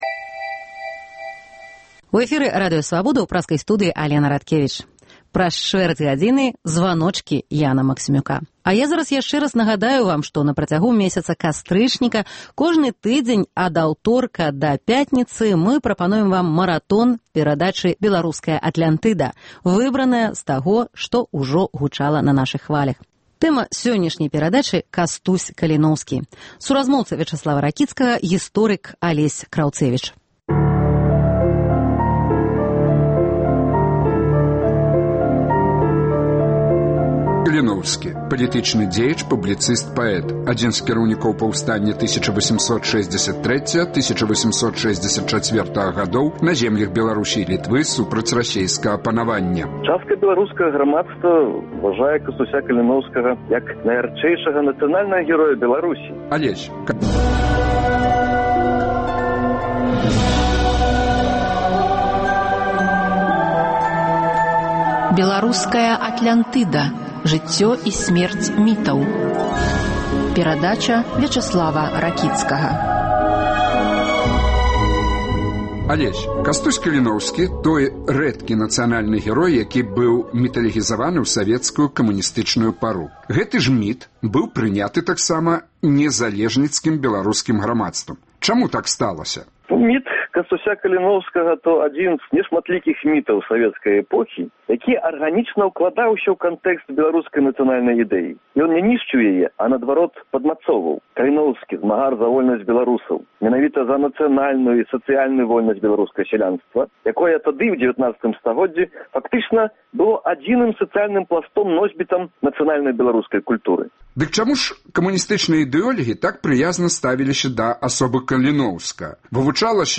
Маратон “Атлянтыдаў”, якія прагучалі на хвалях Свабоды ў сэрыі “Жыцьцё і сьмерць мітаў”.